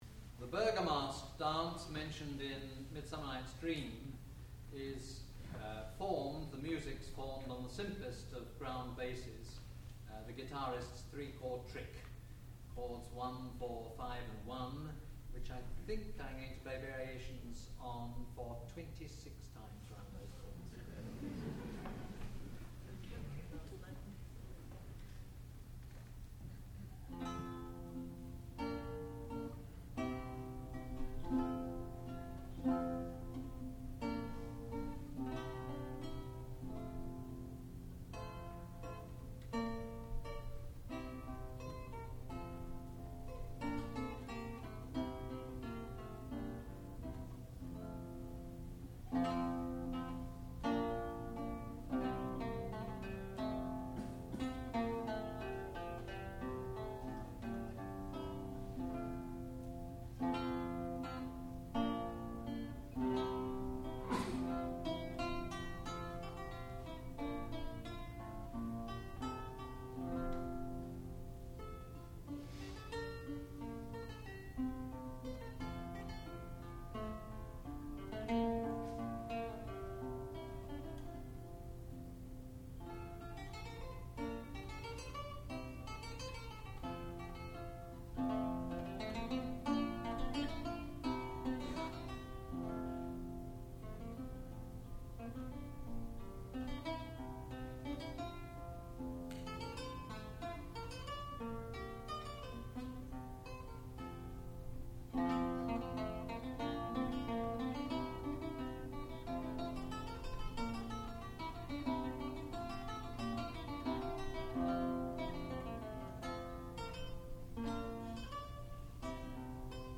Bergomask (lute solo)
sound recording-musical
classical music
lute